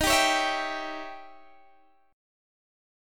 Eb7b9 Chord
Listen to Eb7b9 strummed